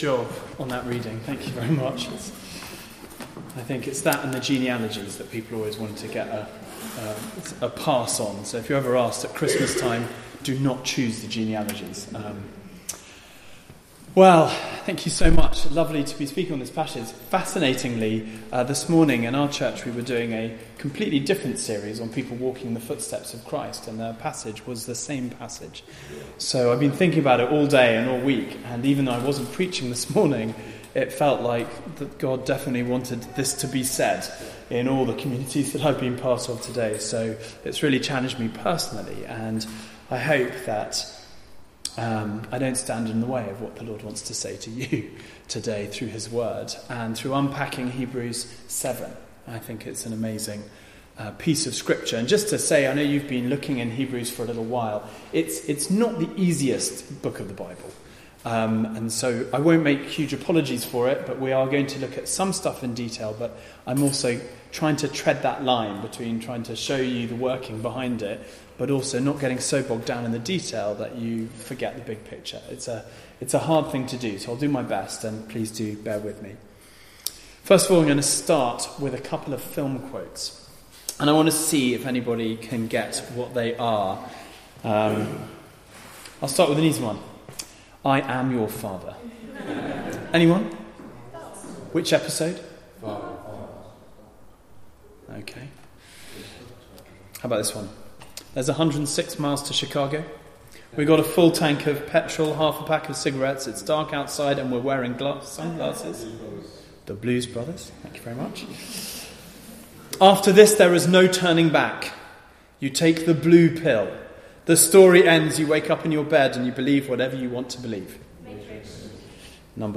Service Type: Weekly Service at 4pm